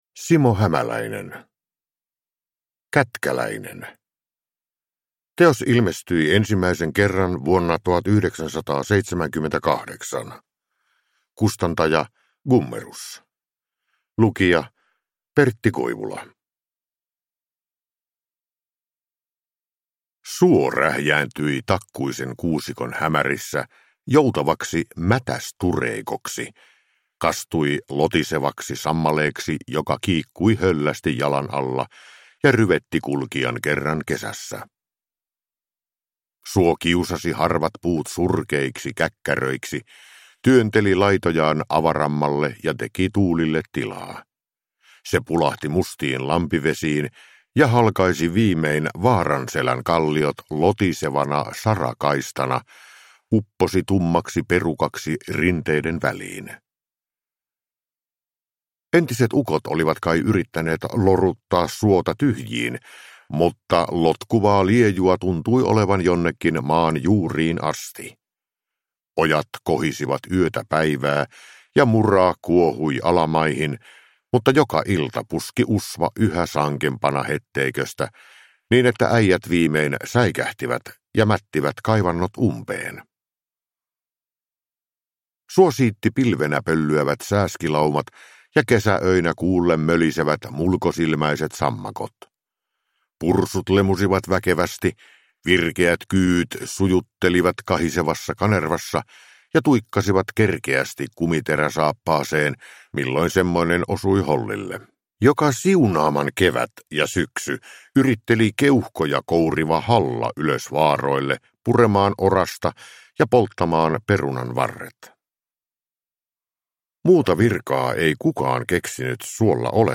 Kätkäläinen – Ljudbok